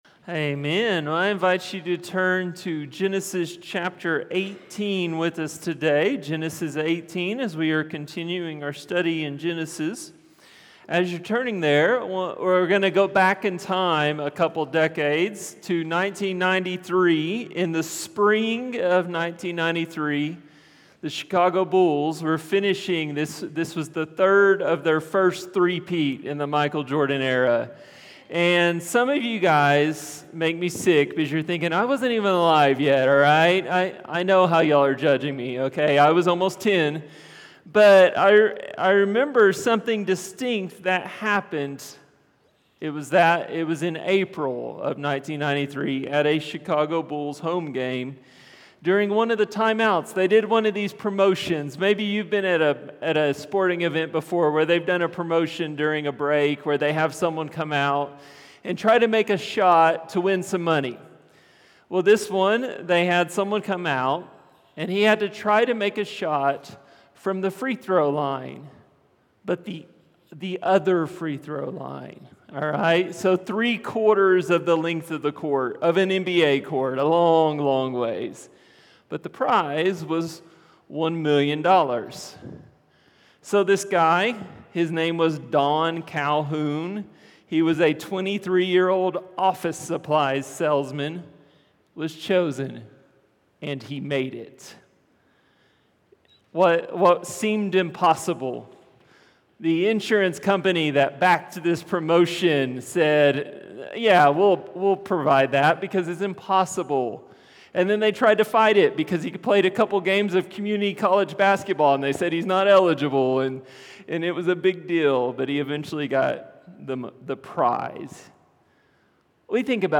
A message from the series "Genesis 12-25."